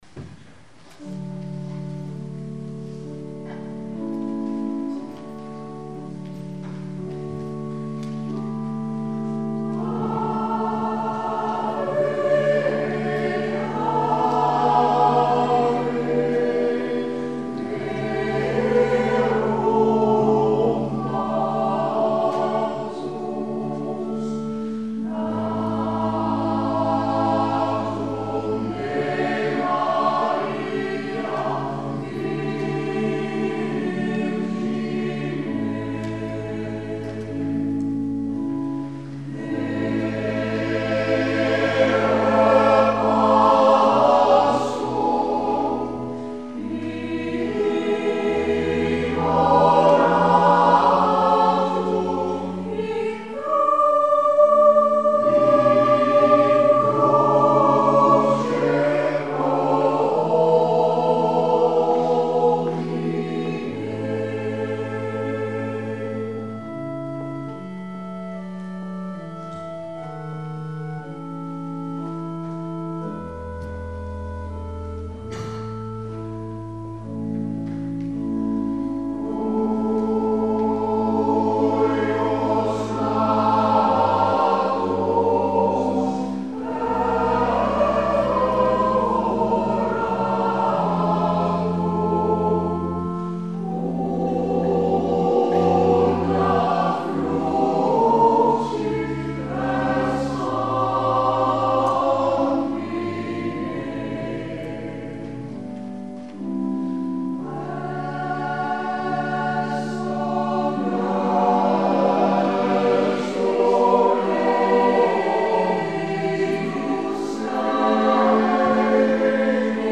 Lied onder de communie: